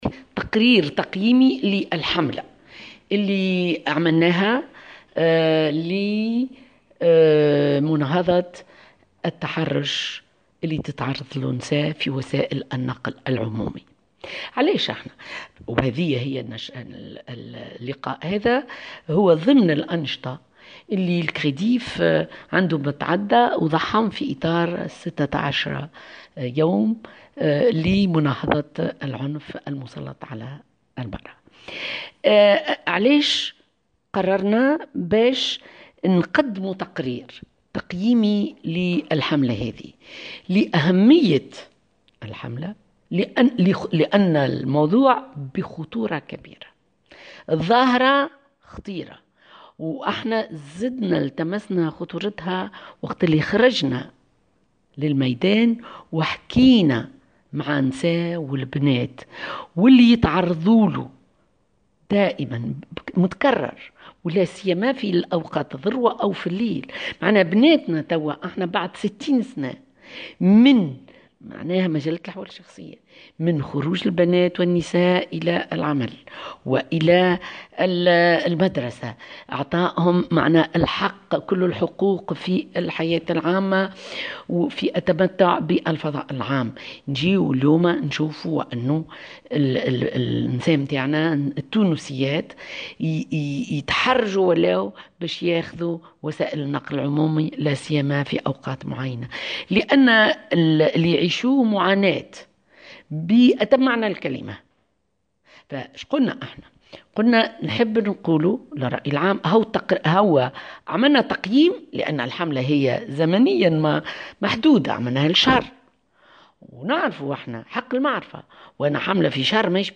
وأضافت في تصريح لـ "الجوهرة أف أم" اليوم الثلاثاء، أن العديد من المواطنات أصبحن يتفادين استخدام وسائل النقل العمومي خاصة في أوقات الذروة لتفادي تعرضهن لمثل هذه الممارسات الإجرامية التي يرتكبها الرجل في حقهن.